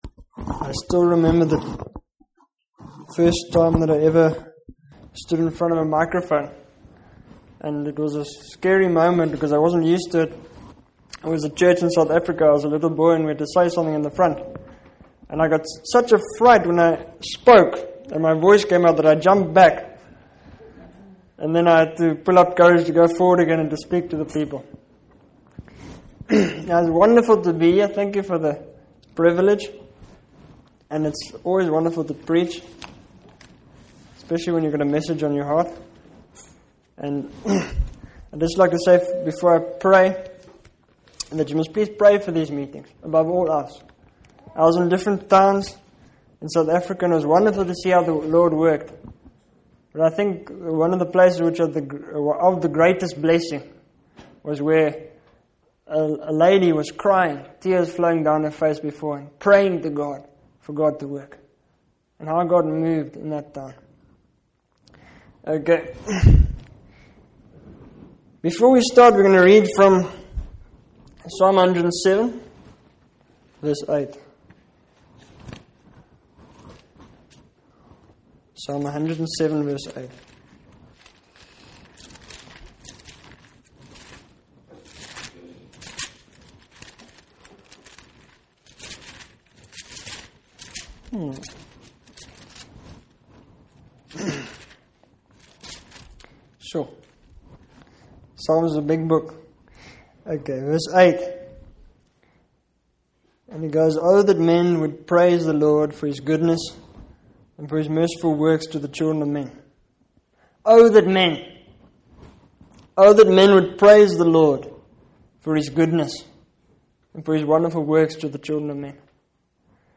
In this sermon, the speaker reflects on the importance of valuing and praising God regardless of the size of the crowd or the circumstances. He shares a personal experience of a disappointing turnout at a meeting, but emphasizes the need to still praise God.